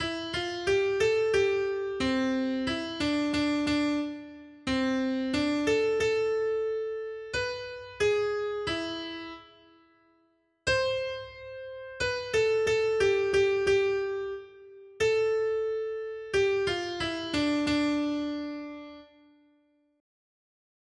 Noty Štítky, zpěvníky ol178.pdf responsoriální žalm Žaltář (Olejník) 178 Skrýt akordy R: Sešli svého ducha, Hospodine, a obnovíš tvář země. 1.